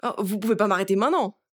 VO_ALL_Interjection_10.ogg